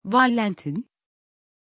Pronunciation of Vallentin
pronunciation-vallentin-tr.mp3